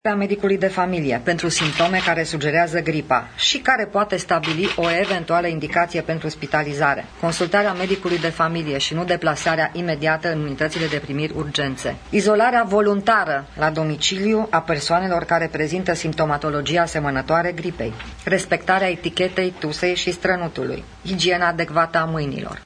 Ministrul sănătății a enumerat și măsurile pe care românii trebuie să le ia pentru a preveni îmbolnăvirea: